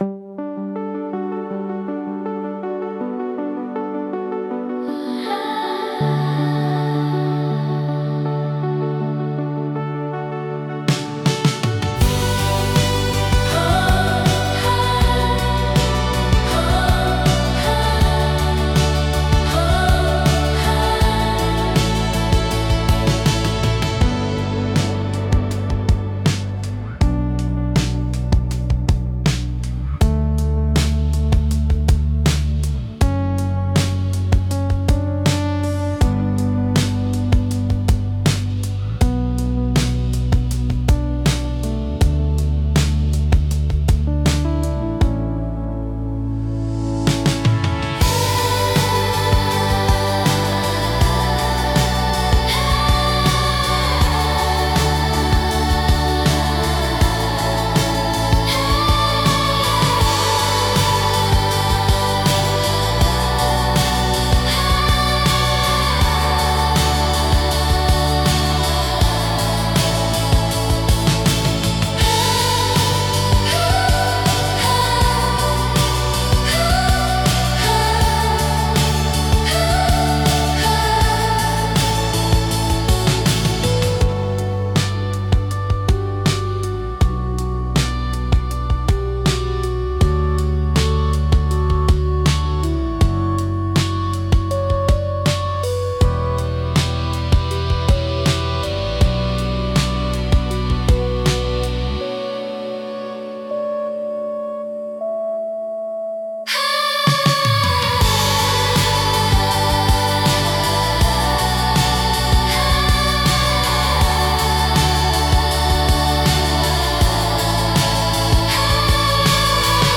BGMセミオーダーシステムドリームポップは、繊細で幻想的なサウンドが特徴のジャンルです。
静かで美しい音の重なりが心地よく、感性を刺激しながらも邪魔にならない背景音楽として活用されます。